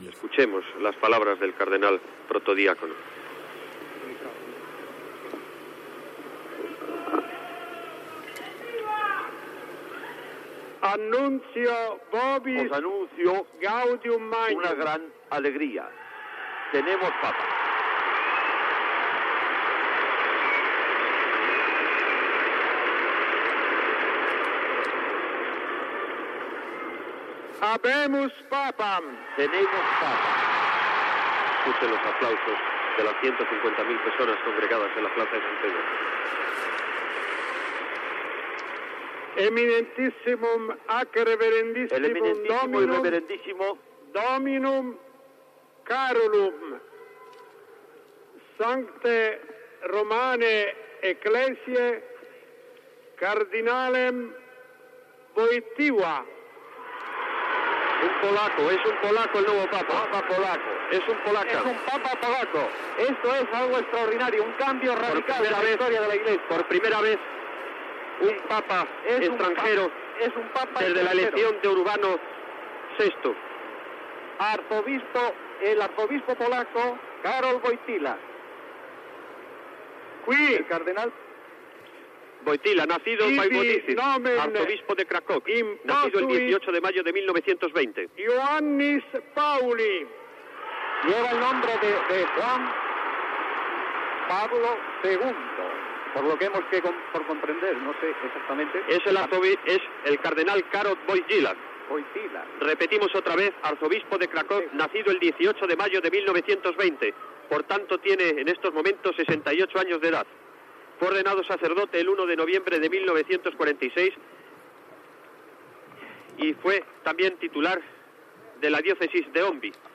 Transmissiò des de la Plaça de Sant Pere de la Ciutat del Vaticà de l'anunci, en llatí, de la proclamació del cardenal polonès Karol Józef Wojtyła com a sant pare Joan Pau II
Informatiu